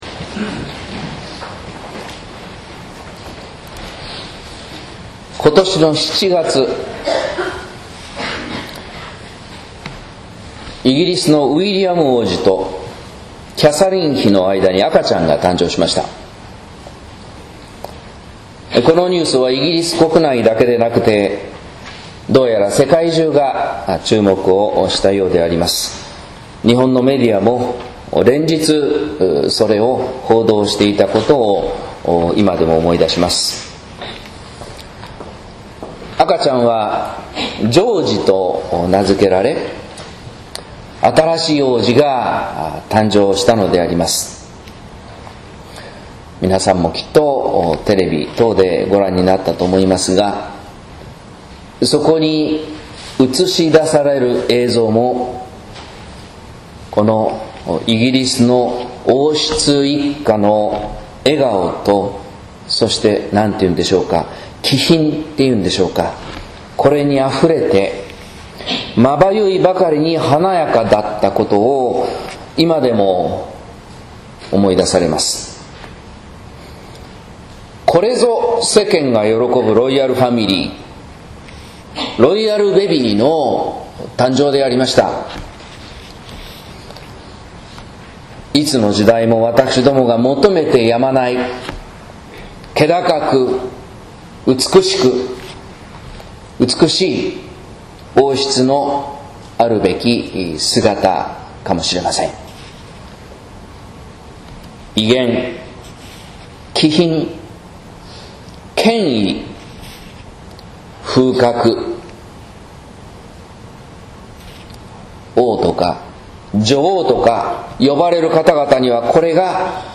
説教「子ロバの威厳、気品、風格」（音声版） | 日本福音ルーテル市ヶ谷教会
待降節第１主日